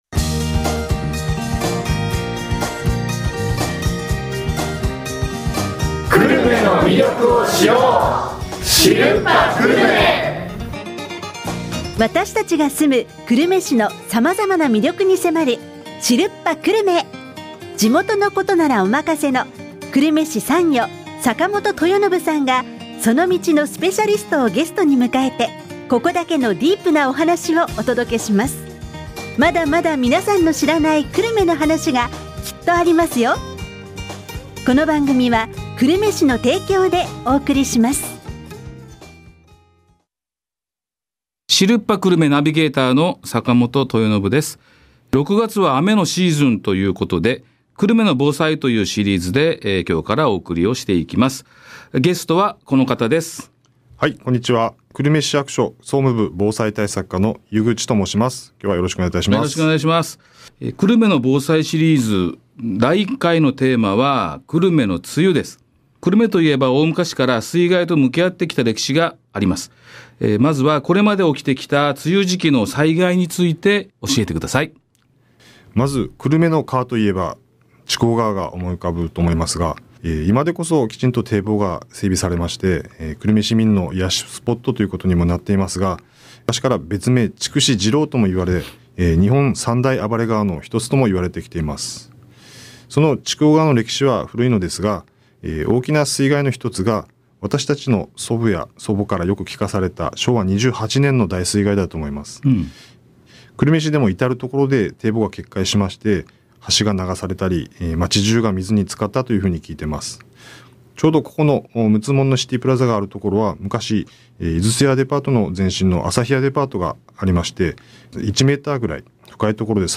ドリームスFM「知るっぱ久留米」（76.5MHz） 毎週木曜日の、12時30分～12時40分に放送！